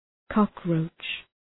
Προφορά
{‘kɒk,rəʋtʃ}